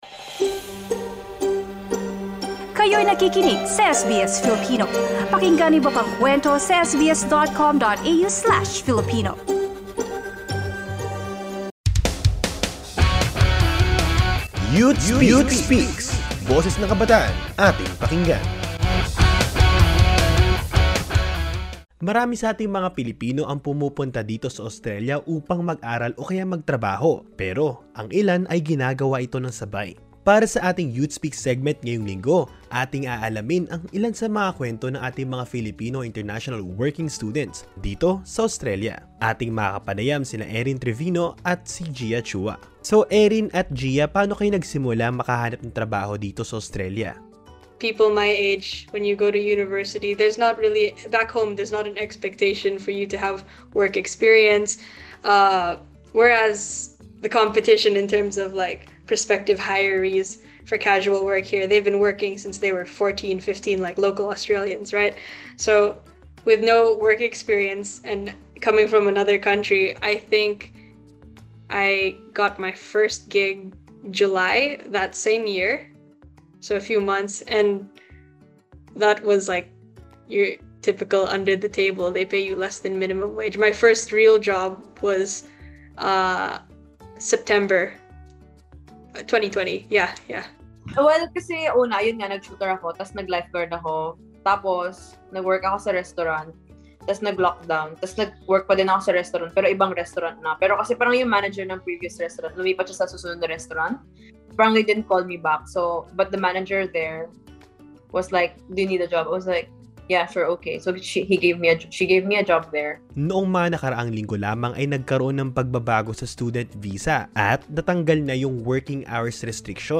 For this week's episode of YouthSpeaks, we have invited two Filipino international students to share their experiences and challenges with how they find balance with their work-school life.